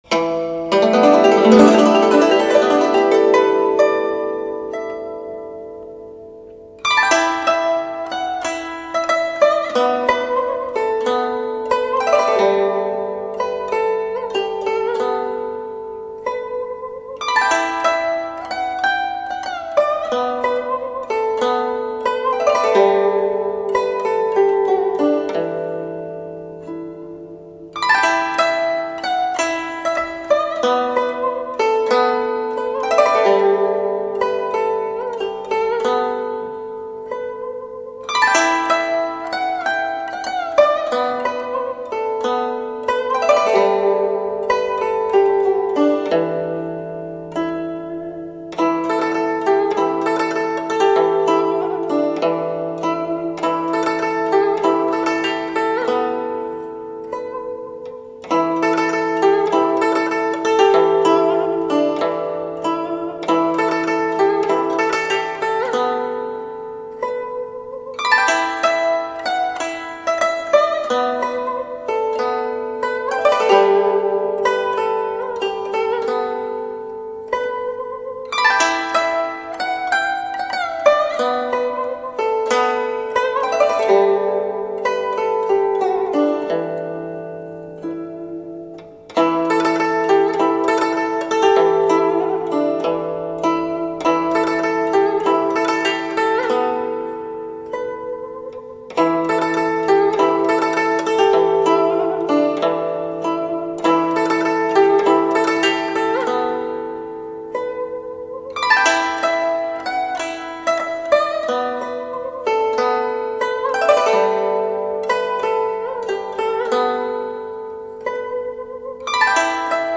佛音 冥想 佛教音乐 返回列表 上一篇： 秋風直直吹-古筝--未知 下一篇： 梦中蝴蝶-古筝--未知 相关文章 楞严咒